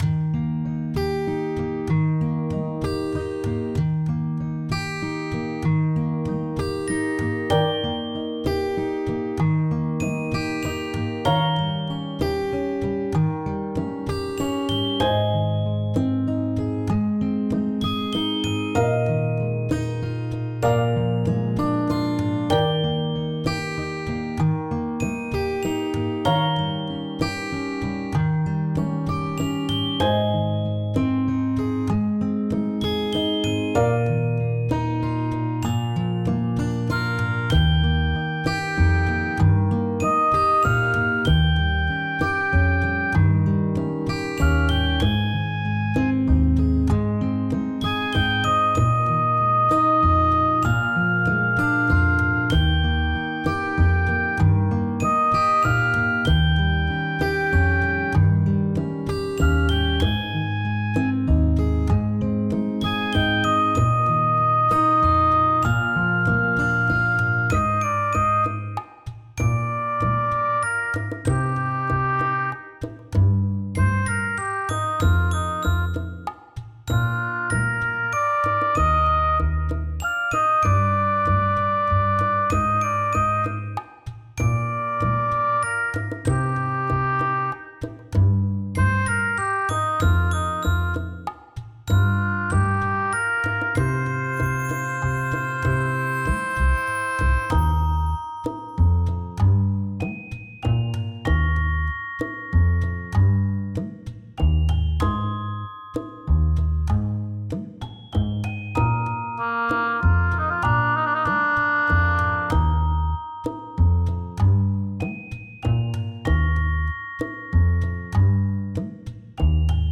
フリーBGM素材- 夕方くらいの帰り道、のんびり寄り道。ちょっとねむそう。